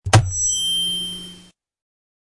Download Dropping the Mic sound effect for free.